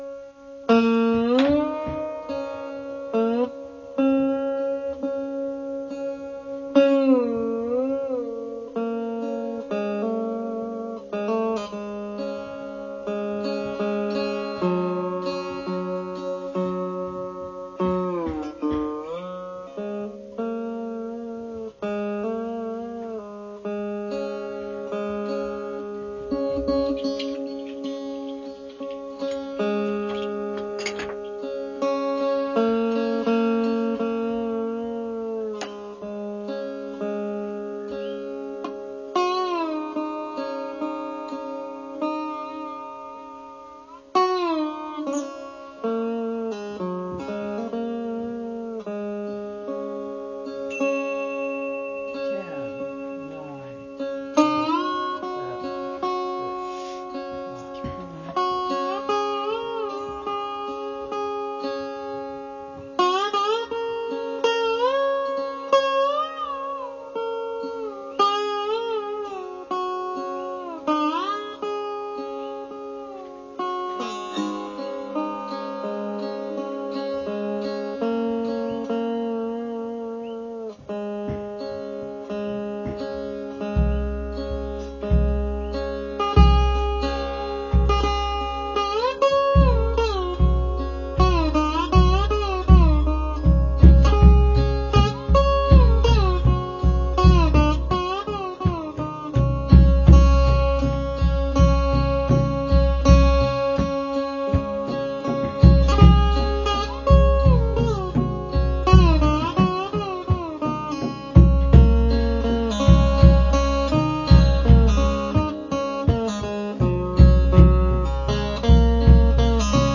22-string slide veena
The audio example here is a shortened version of Raga Durga, a long piece celebrating the “Wonder Woman” myth of India. The veena starts slowly and quietly and gradually speeds up, adding percussion.